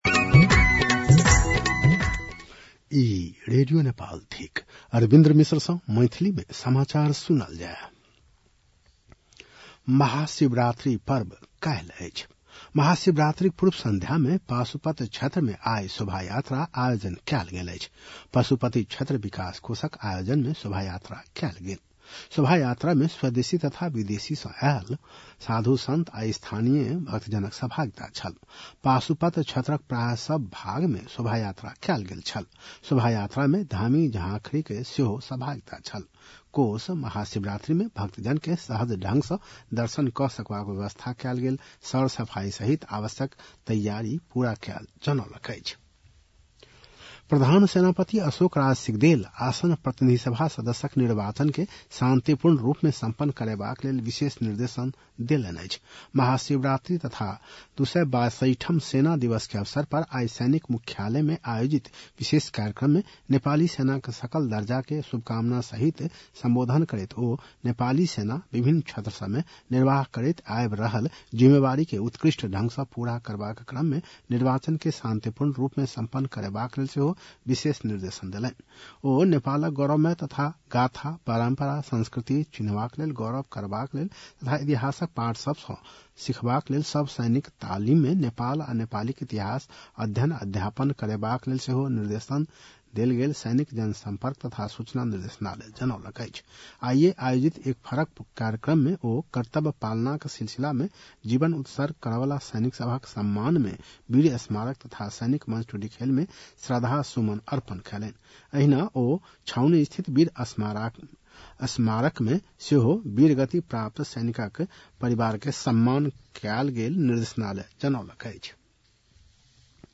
मैथिली भाषामा समाचार : २ फागुन , २०८२
6.-pm-maithali-news-1-5.mp3